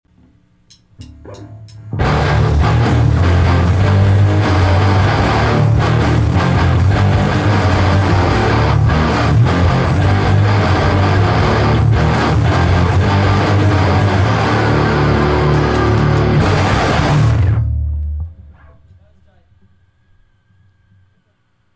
They are a very tongue-in-cheack hardcore band.